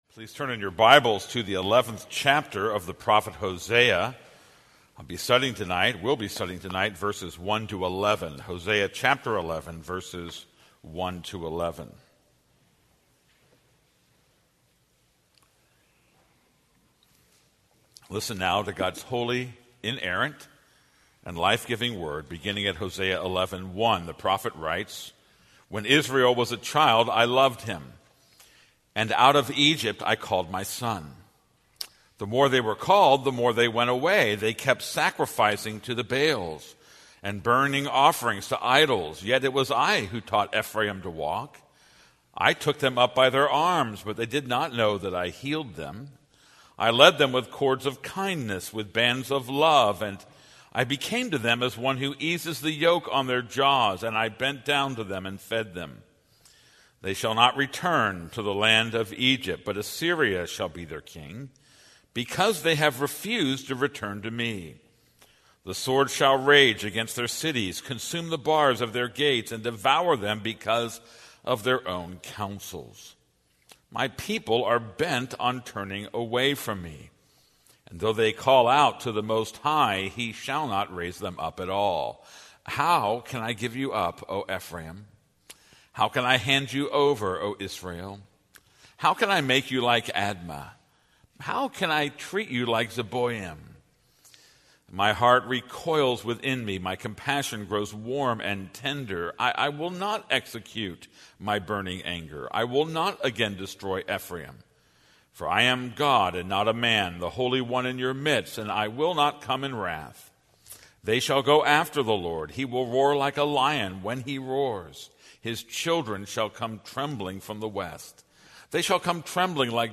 This is a sermon on Hosea 11:1-9.